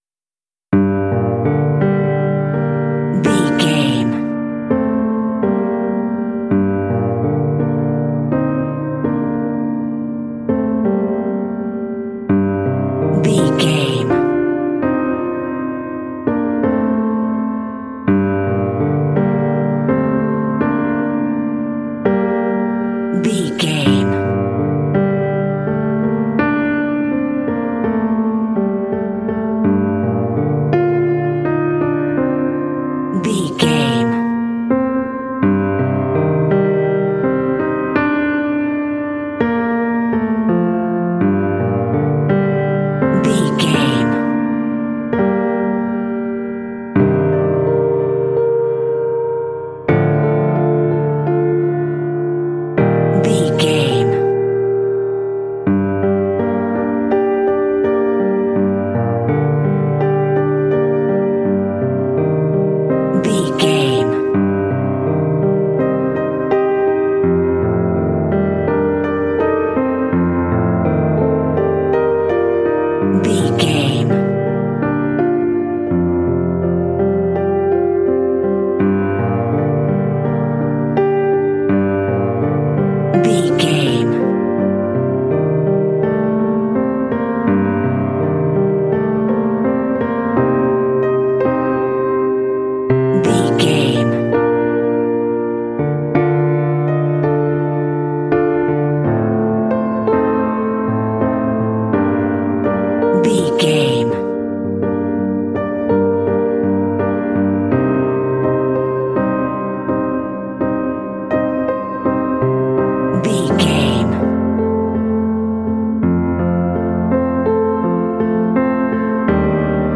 Aeolian/Minor
melancholy
elegant
calm
reflective
peaceful
dramatic
piano
contemporary underscore